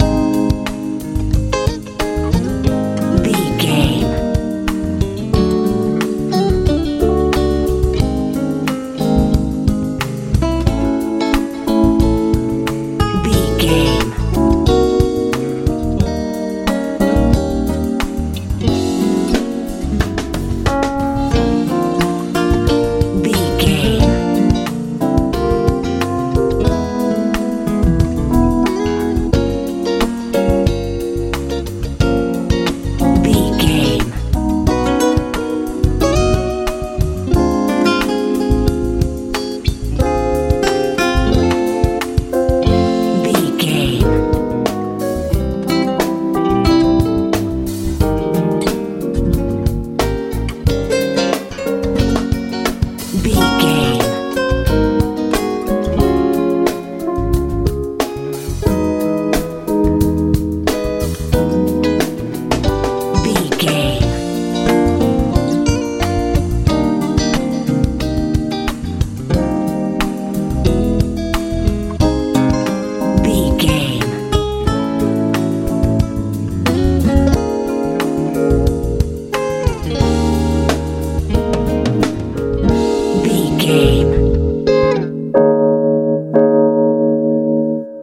cool jazz pop feel
Ionian/Major
A♭
soothing
smooth
piano
bass guitar
drums
electric guitar